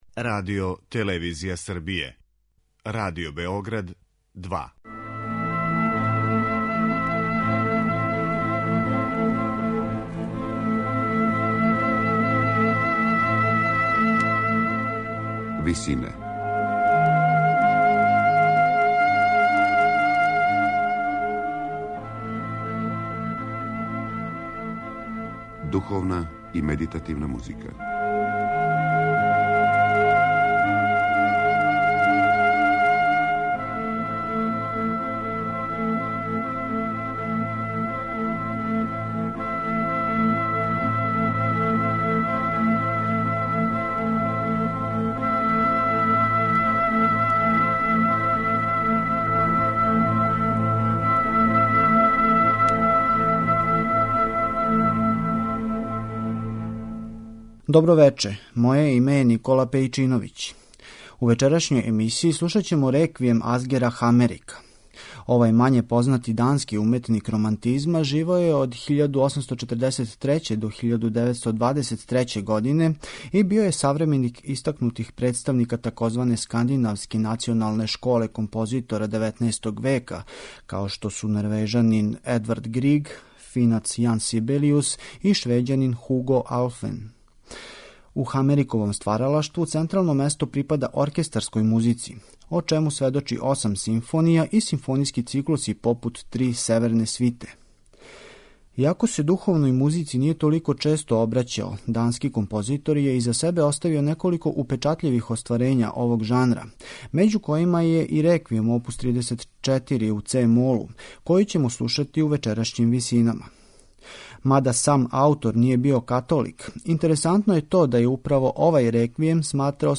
Реквијем, Асгера Хамерика
медитативне и духовне композиције